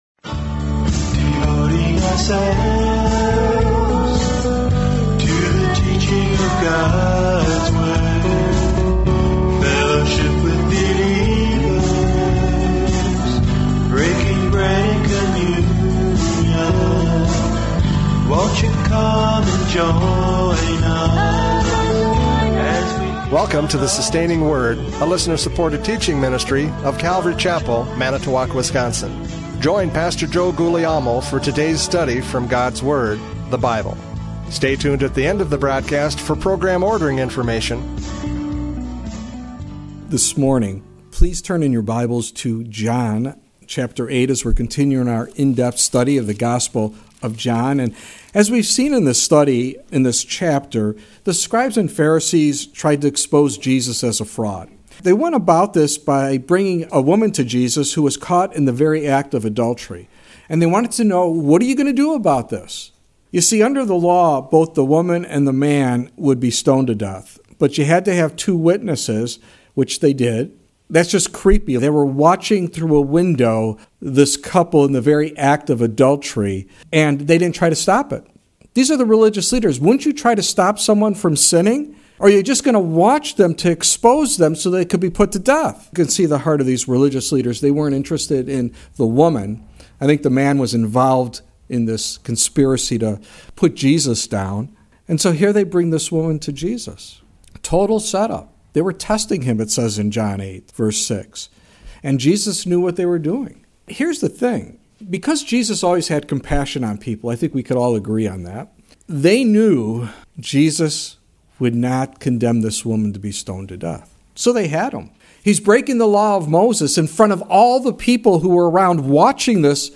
John 8:31-38 Service Type: Radio Programs « John 8:21-30 Life and Death!